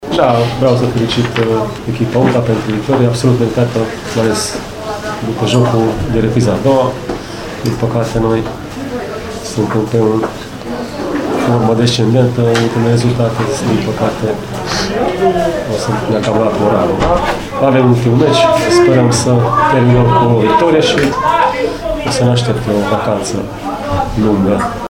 a fost extrem de succint la conferința de presă de după meci, felicitând UTA pentru victorie: